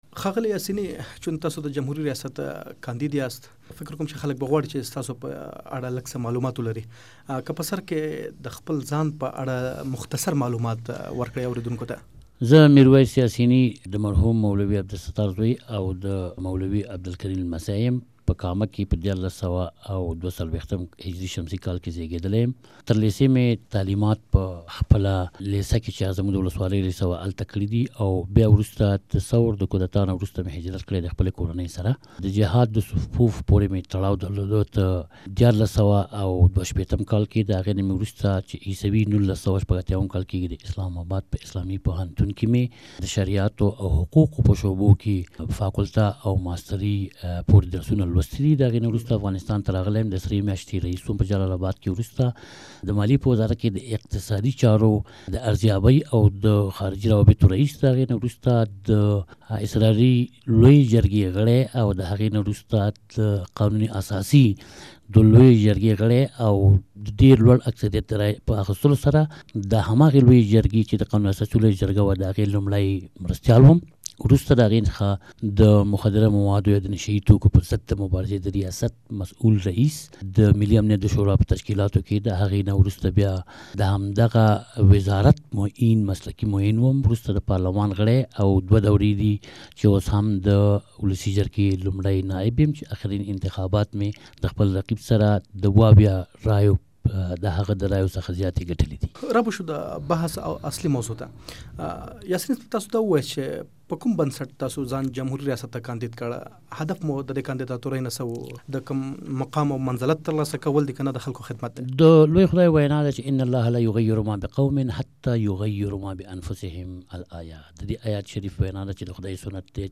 د ولسمشرۍ د څوکۍ له کاندید میرویس یاسیني سره مرکه واورﺉ